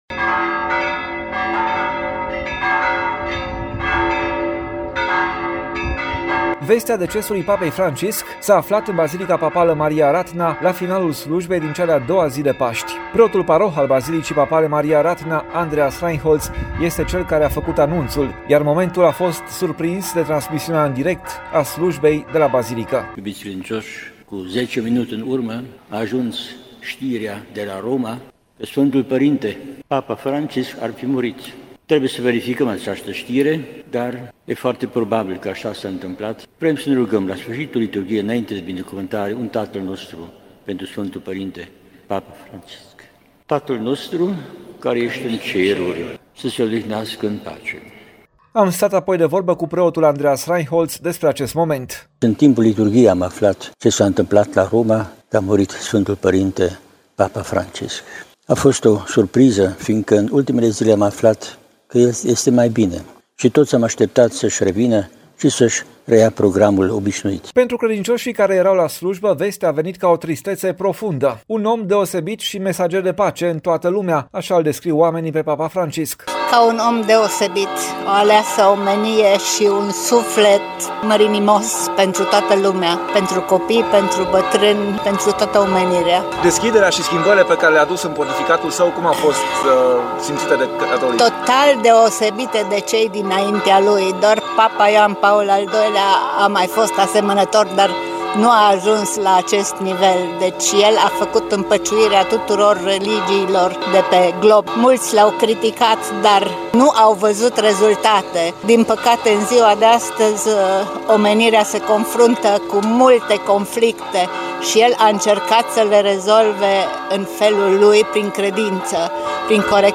M-am emoționat”, a spus cu voce tremurândă o femeie aflată la slujbă.
A fost anunțat de către preot”, a adăugat o altă femeie aflată în pelerinaj.